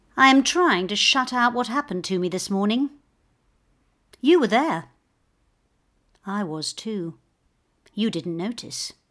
‘Touch’ and listen to the emotions and thoughts of this heartbroken woman.